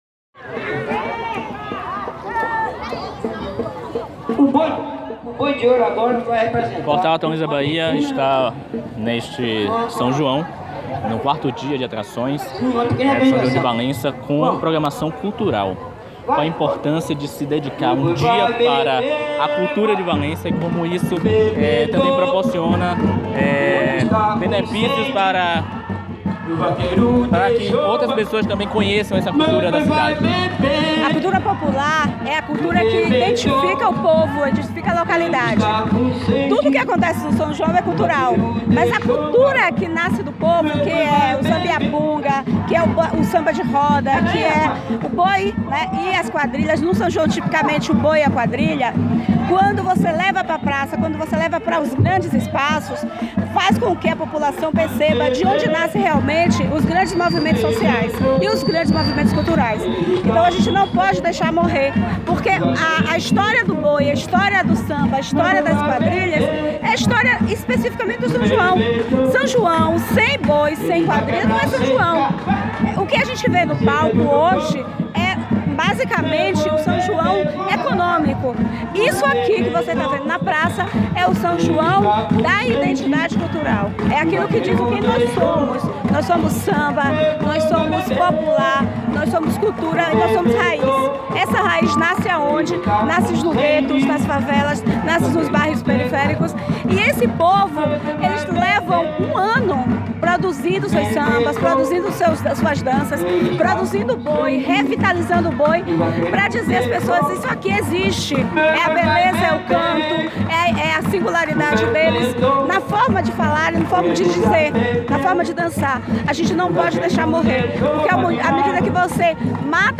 A secretária de Cultura, Janete Vomeri, comentou a importância de apoiar a cultura. Confira a entrevista: